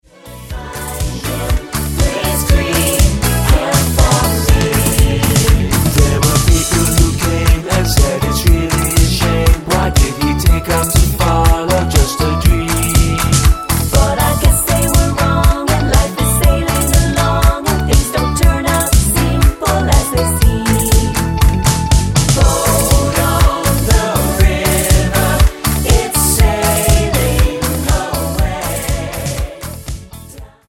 --> MP3 Demo abspielen...
Tonart:A mit Chor